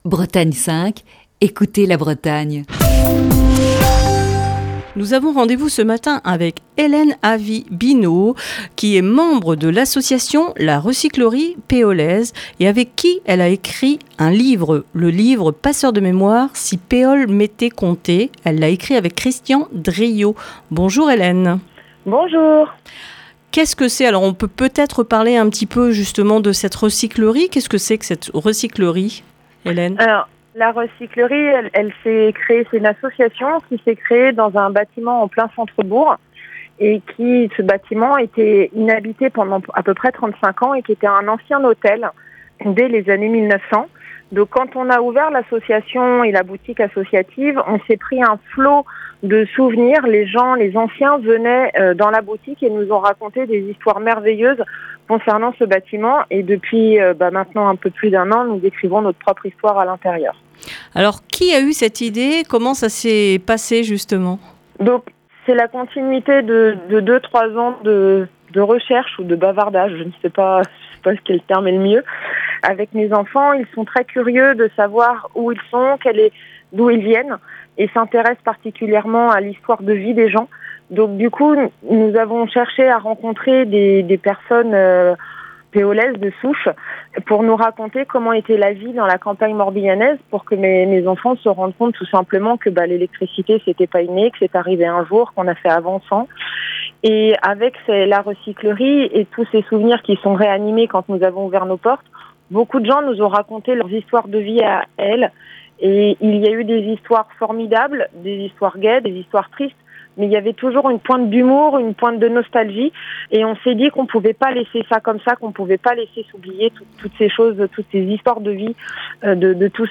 Ce vendredi dans le coup de fil du matin